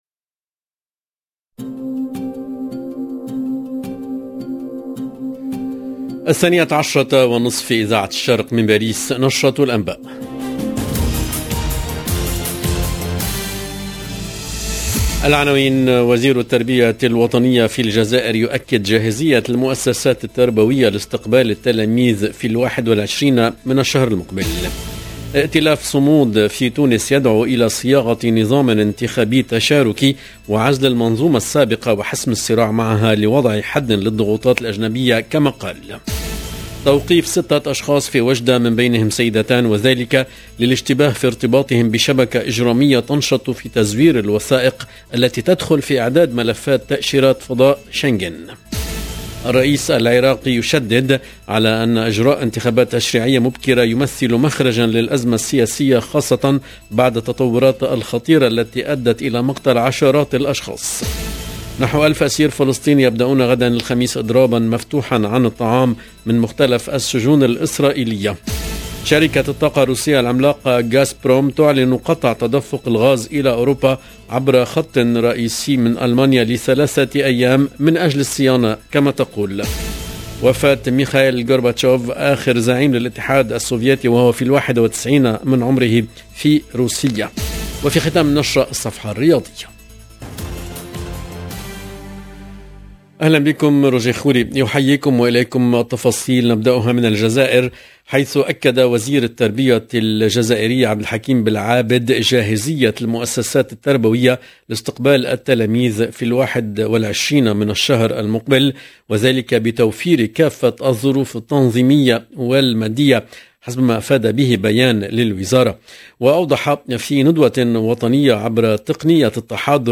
LE JOURNAL DE 12H30 EN LANGUE ARABE DU 31/8/2022
EDITION DU JOURNAL DE 12H30 DU 31/8/2022